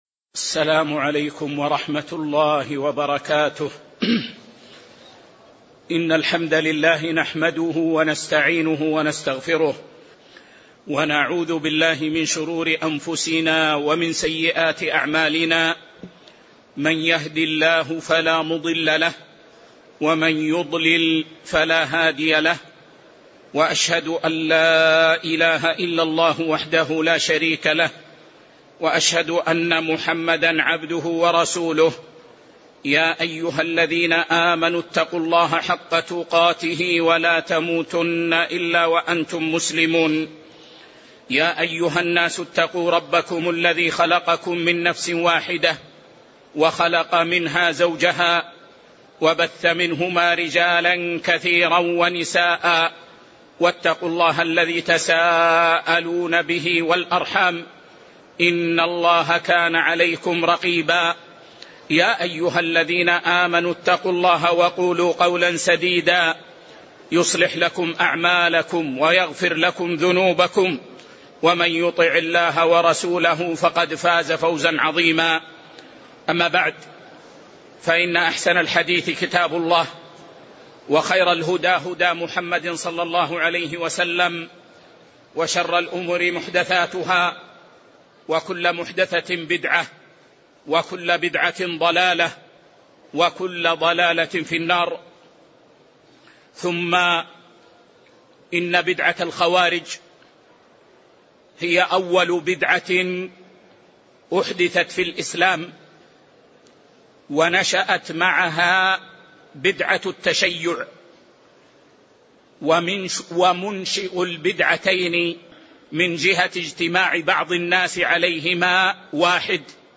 محاضرة
المـسجد النـبوي